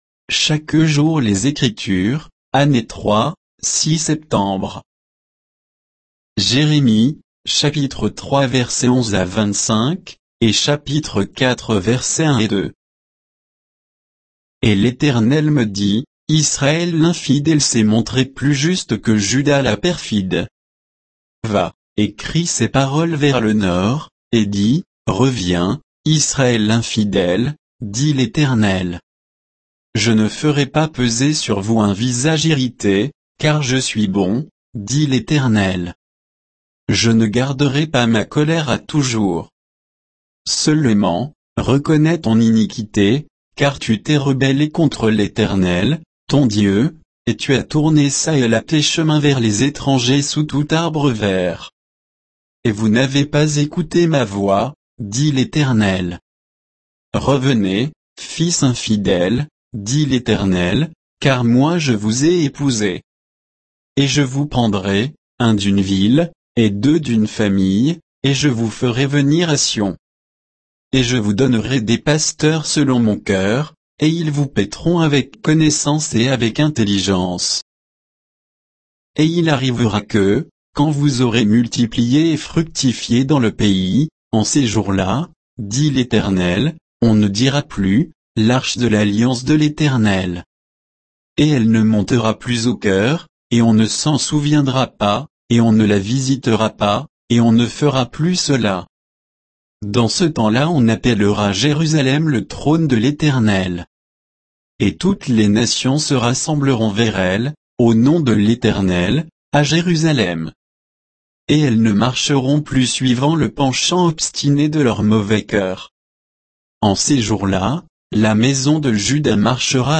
Méditation quoditienne de Chaque jour les Écritures sur Jérémie 3